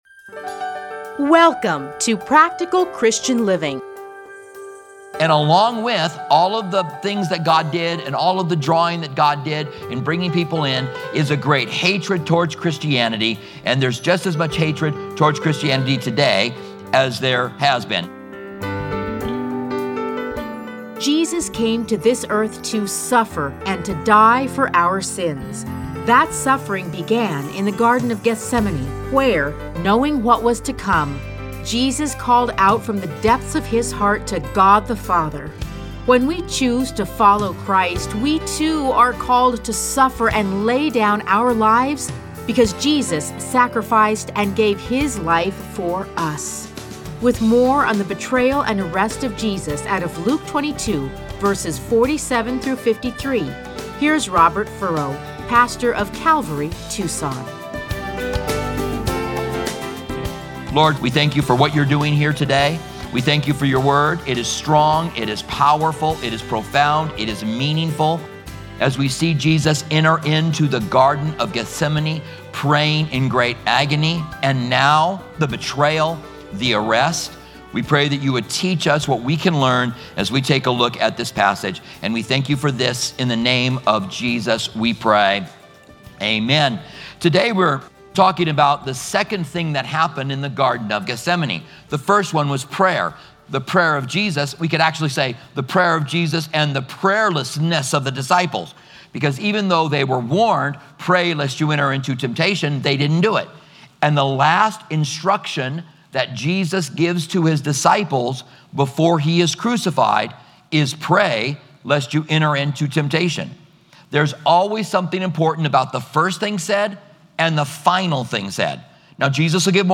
Listen to a teaching from Luke 22:47-53.